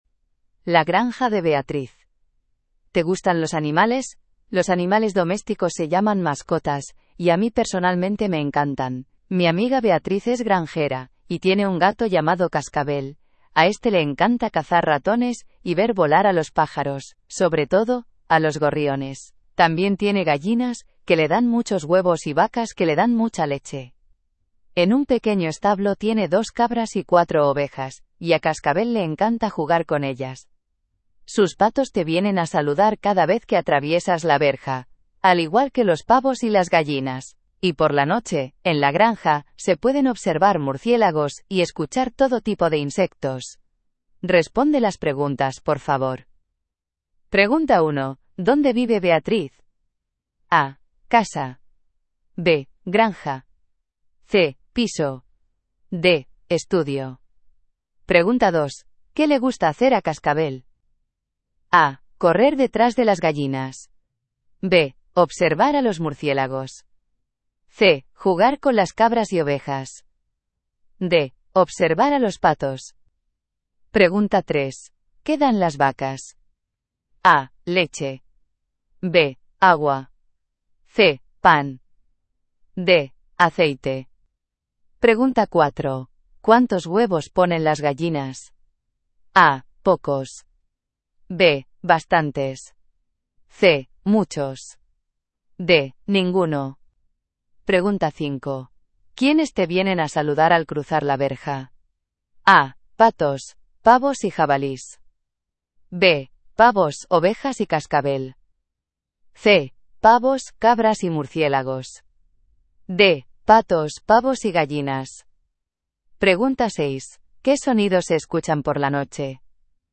Hiszpania